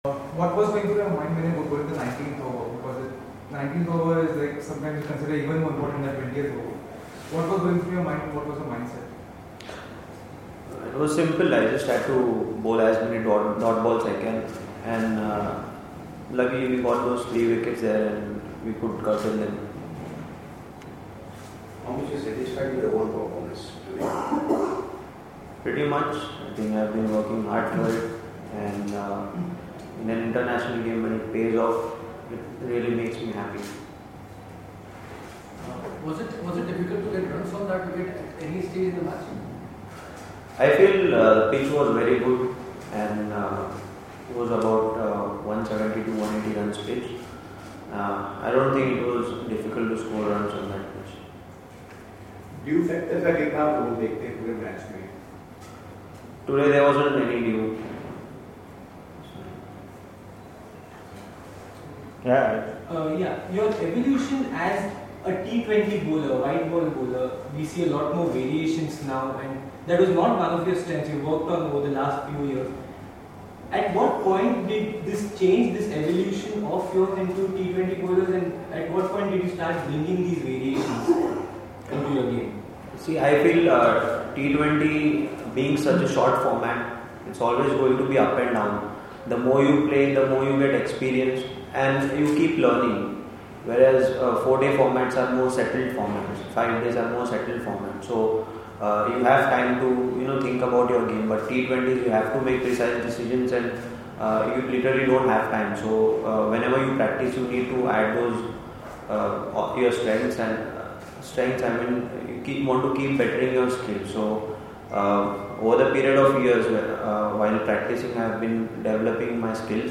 Shardul Thakur spoke to the media in Indore on Tuesday after India win the 2nd T20I against Sri Lanka.